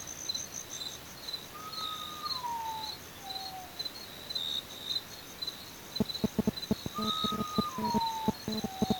Urutaú Común (Nyctibius griseus)
Vocalizando 2 individuos
Nombre en inglés: Common Potoo
Condición: Silvestre
Certeza: Vocalización Grabada